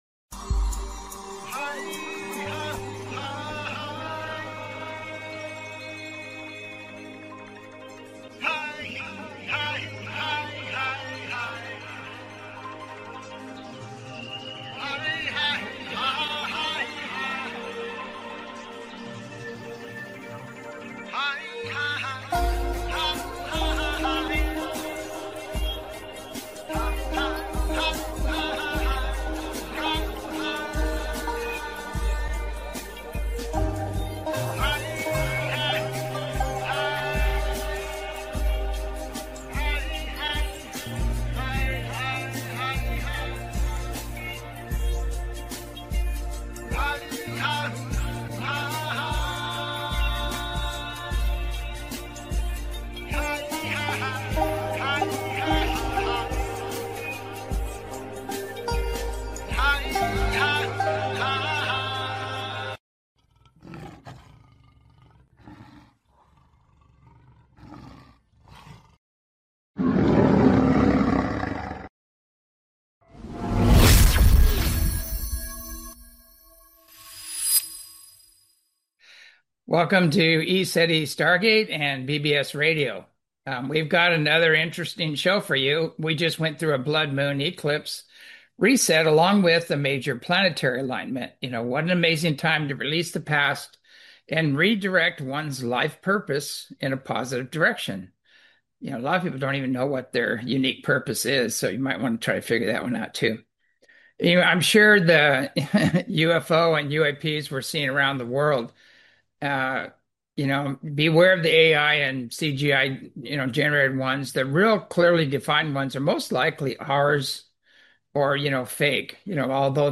Talk Show Episode, Audio Podcast, As You Wish Talk Radio and Collapse of the Controlled Narrative After UFO on , show guests , about Collapse of the Controlled Narrative,UFO,Geopolitical Resets,Fall of the Global Elite,Shadow Government,Middle East conflict,ufo disclosure,Mainstream Suppression, categorized as Earth & Space,News,Paranormal,UFOs,Politics & Government,Society and Culture,Spiritual,Access Consciousness,Theory & Conspiracy